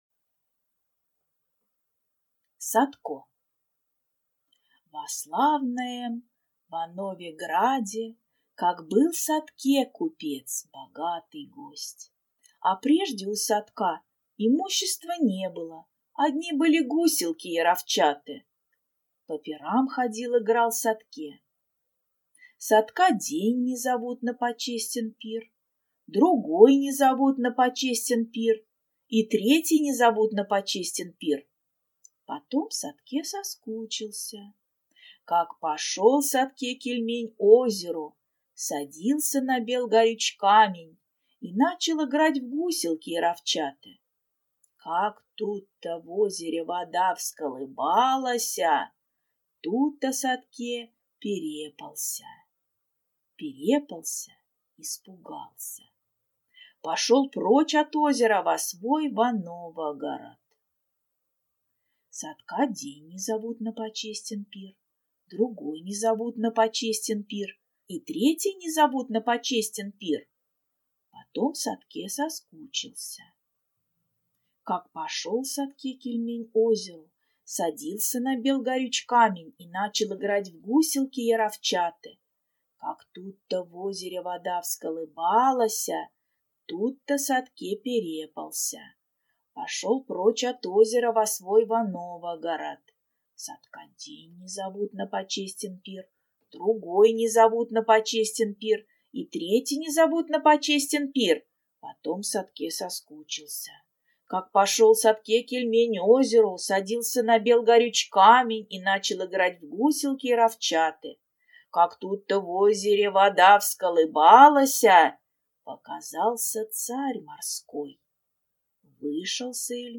Аудиокнига Садко | Библиотека аудиокниг